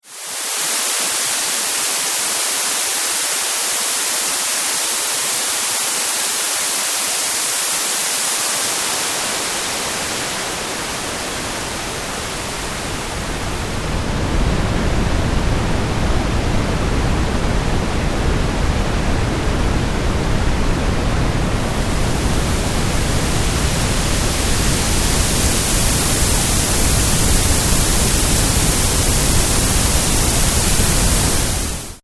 ブルー〜レッド・ノイズと、そのミックス